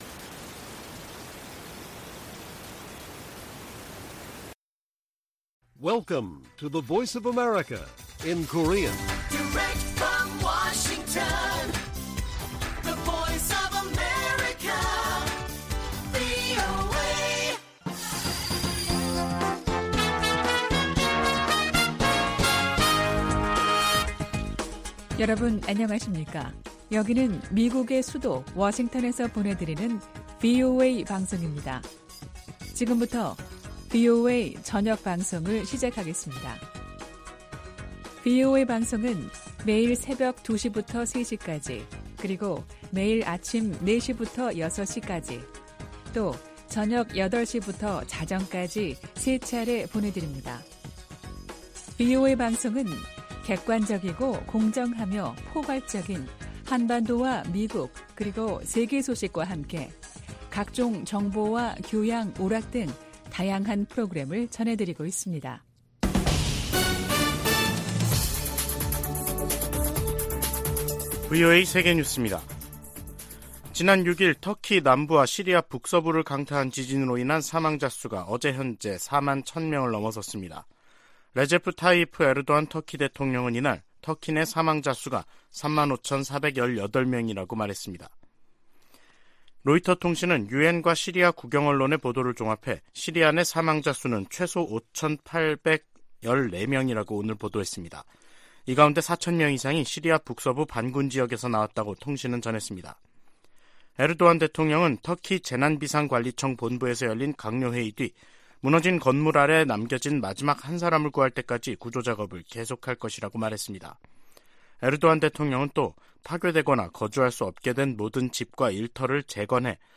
VOA 한국어 간판 뉴스 프로그램 '뉴스 투데이', 2023년 2월 15일 1부 방송입니다. 미 국무부가 중국의 정찰풍선 문제를 거론하며, 중국을 미한일 3국의 역내 구상을 위협하는 대상으로 규정했습니다. 백악관은 중국의 정찰풍선 프로그램이 정부의 의도와 지원 아래 운용됐다고 지적했습니다. 북한은 고체연료 ICBM 부대를 창설하는 등, 핵무력 중심 군 편제 개편 움직임을 보이고 있습니다.